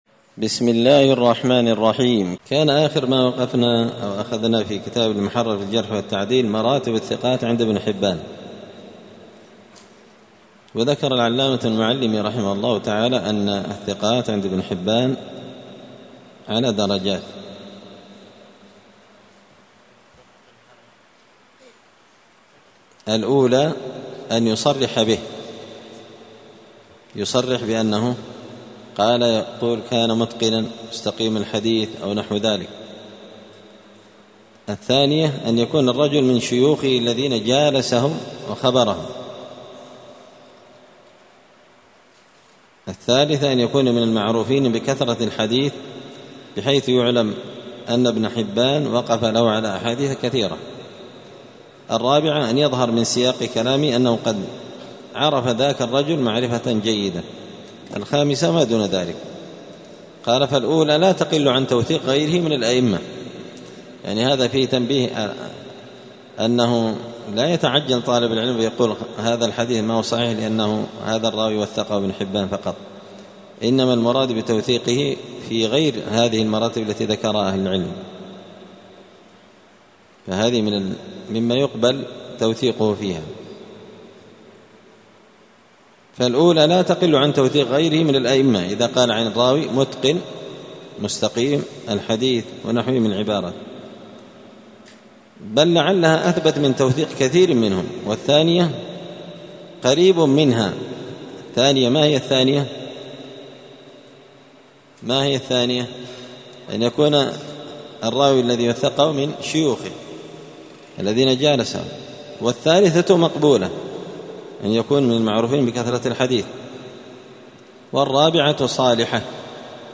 *الدرس الرابع والأربعون (44) تابع لمراتب الثقات عند ابن حبان*